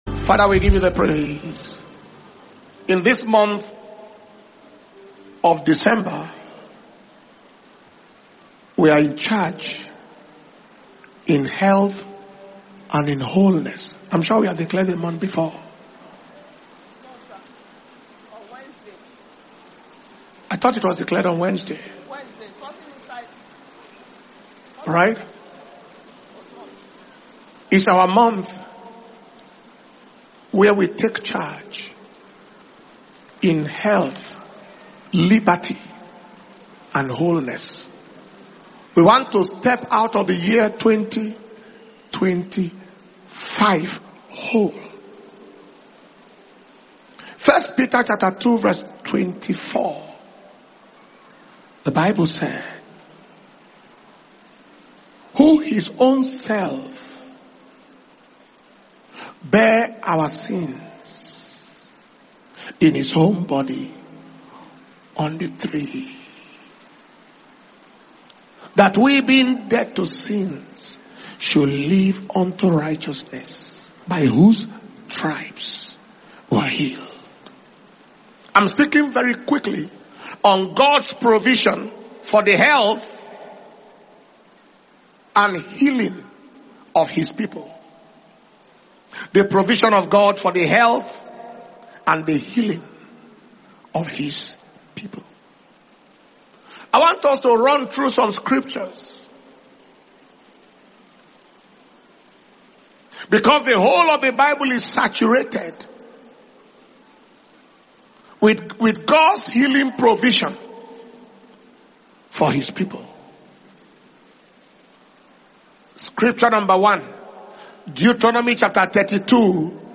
December 2025 Blessing Sunday Service